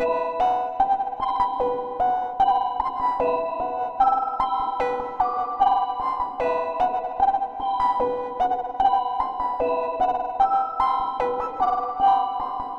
melodyloop 1 (150 bpm).wav